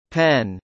英語：/ pen /